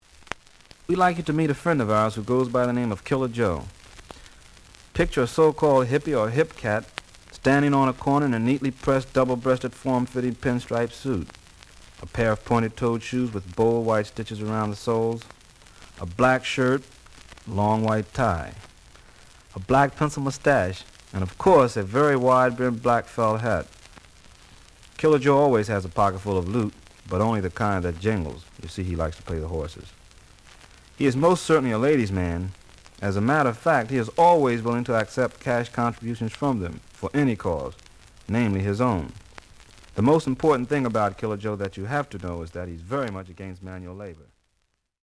The audio sample is recorded from the actual item.
●Genre: Hard Bop